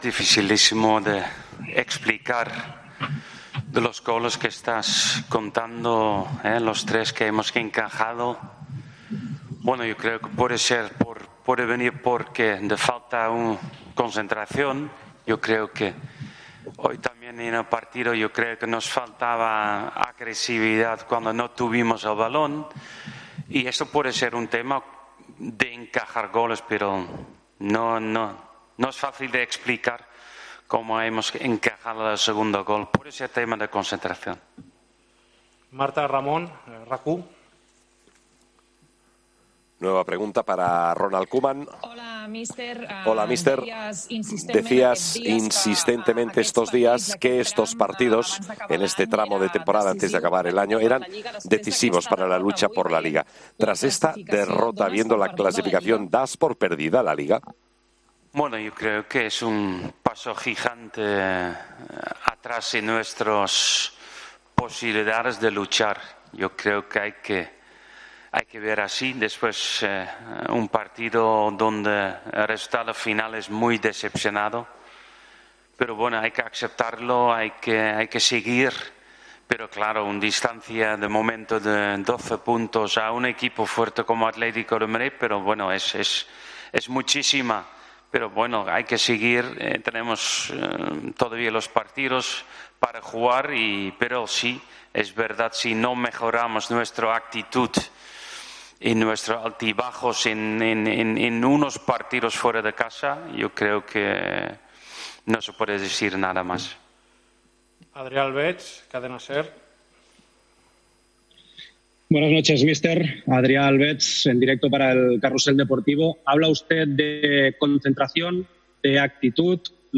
EN RUEDA DE PRENSA
El entrenador azulgrana explicó en rueda de prensa la derrota del Barcelona ante el Cádiz.